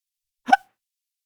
Hipo | SÓNEC | Sonoteca de Música Experimental y Arte Sonoro
Fenómeno humano e involuntario provocado diafragma
Hipo.mp3